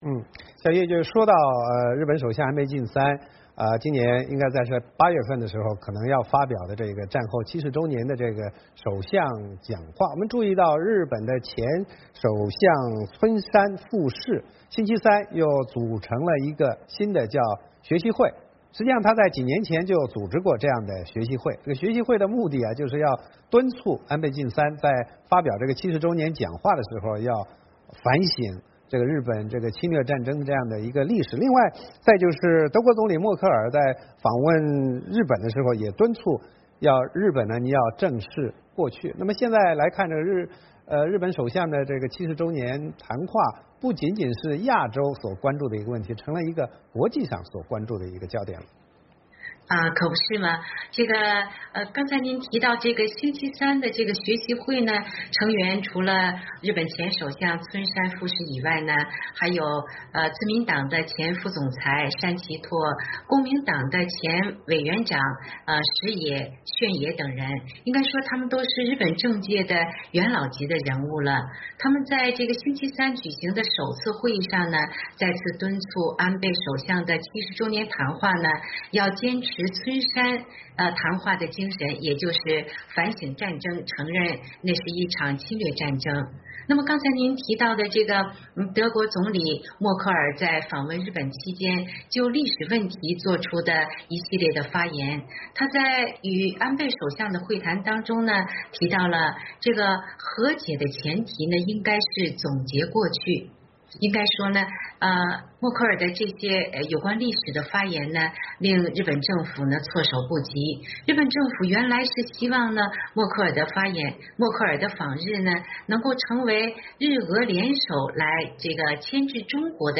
VOA连线：日前首相村山“学习会”敦促反省战争